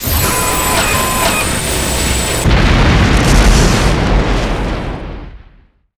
combat / suit / miss.wav
miss.wav